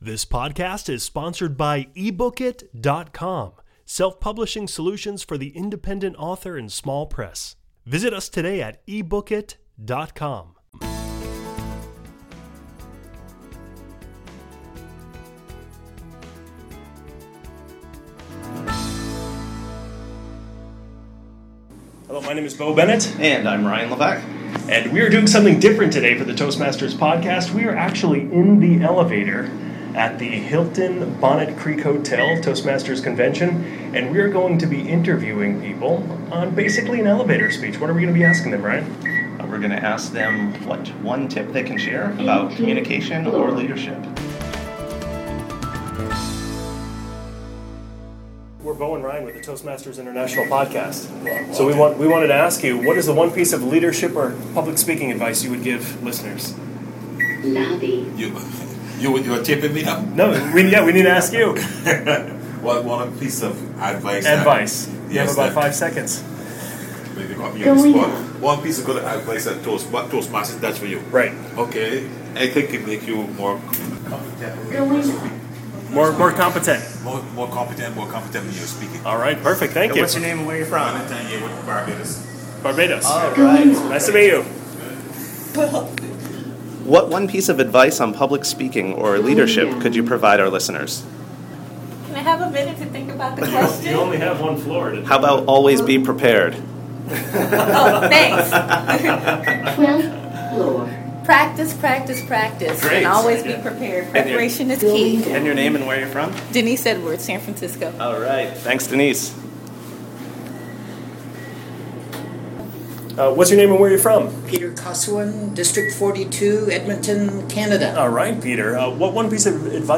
#061: 2012 International Convention Live Interviews - Elevator Speeches | Toastmasters Podcast